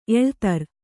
♪ eḷtar